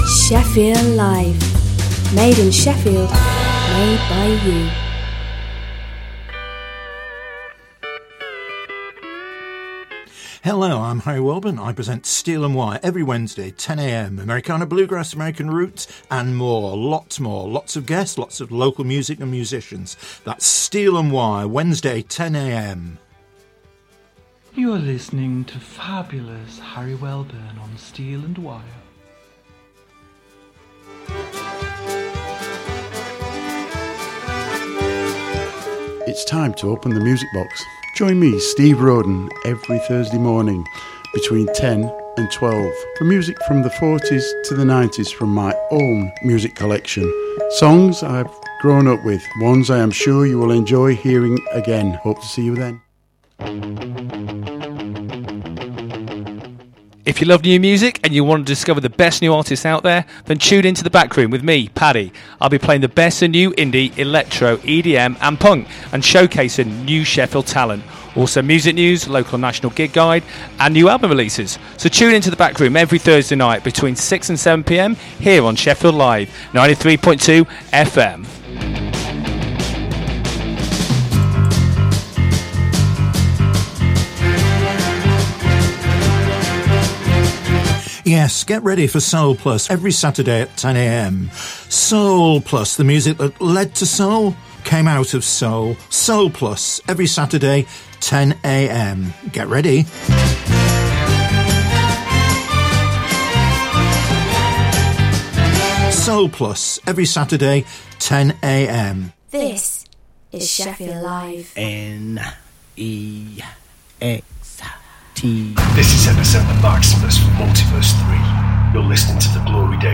2 hours of the best popular classic and Prog rock music plus Gig and band info..